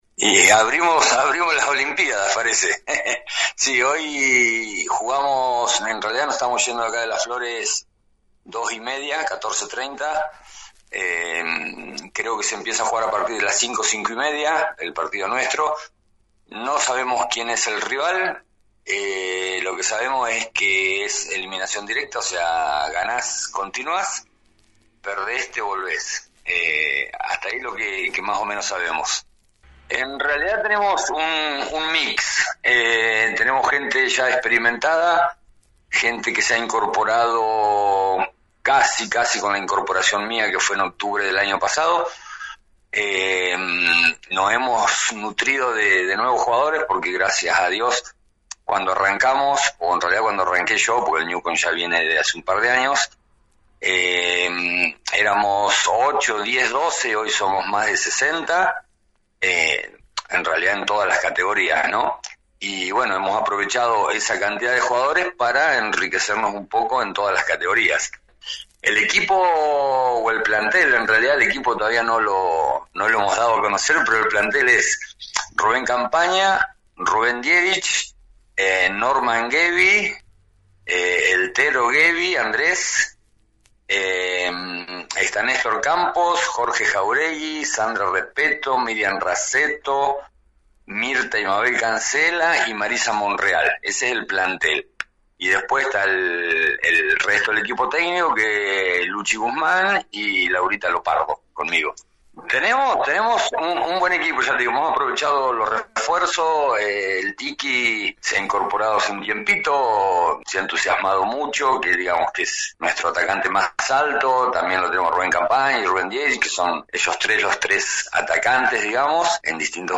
En diálogo con la 91.5